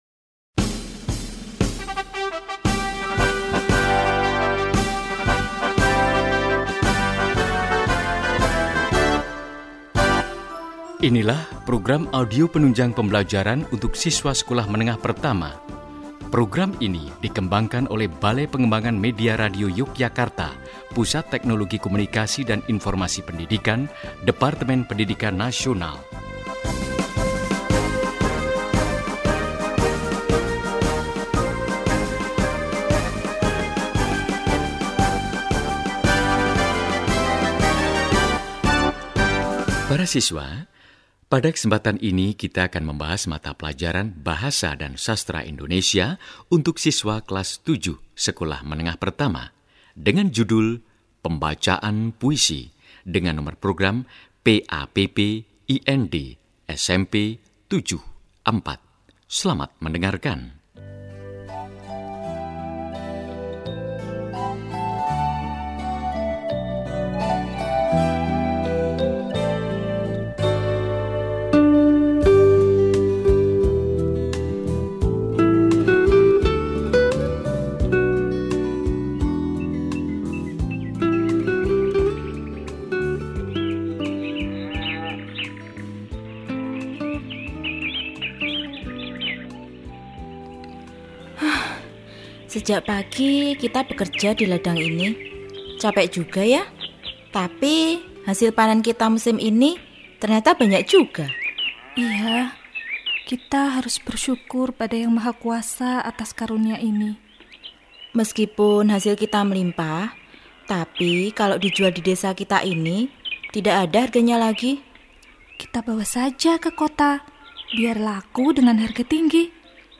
Pembacaan Puisi Perempuan-perempuan Perkasa
679_pembacaan_puisi_perempuan-perempuan_perkasa.mp3